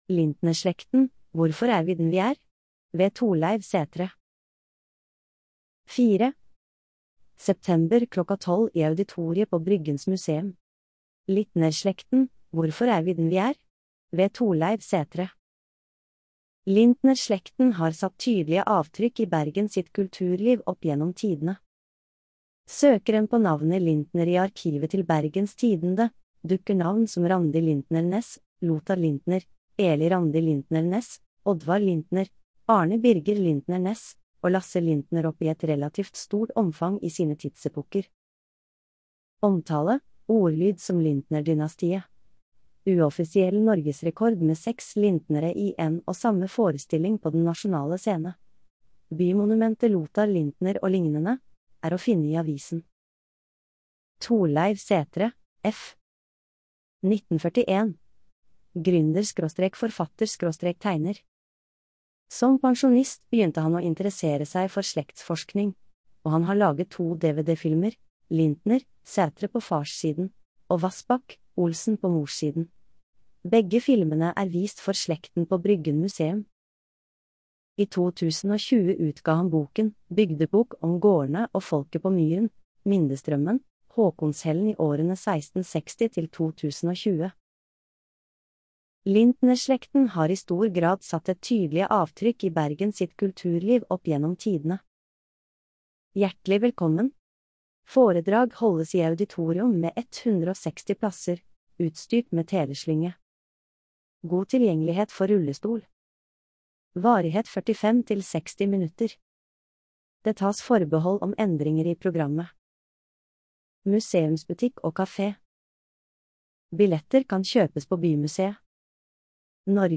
Populærvitenskapelige foredrag. Bryggens Museum